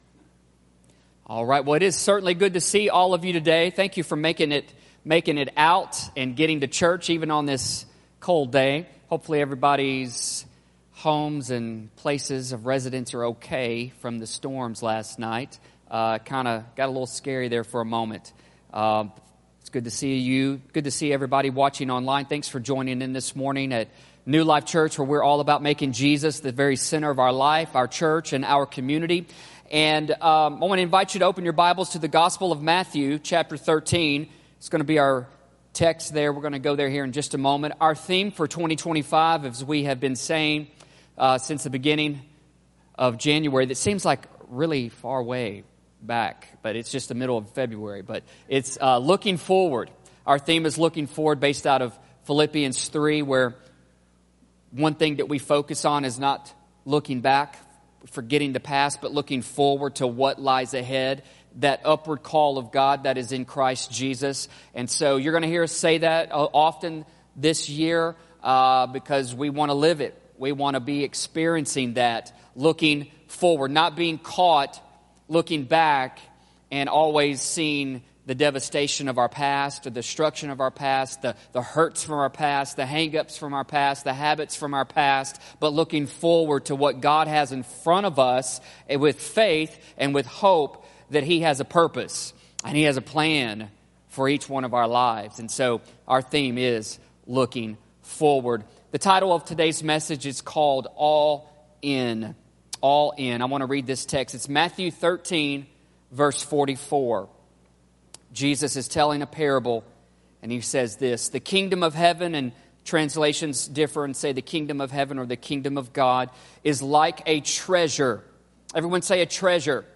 New Life Church Sermons